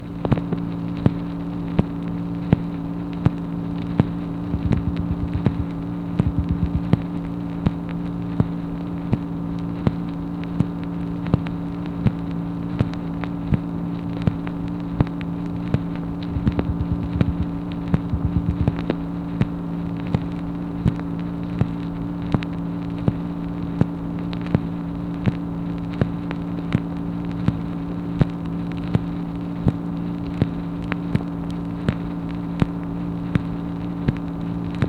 MACHINE NOISE, April 10, 1964
Secret White House Tapes | Lyndon B. Johnson Presidency